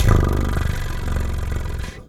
cat_2_purr_03.wav